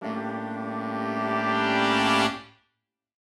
Index of /musicradar/gangster-sting-samples/Chord Hits/Horn Swells
GS_HornSwell-A7b2sus4.wav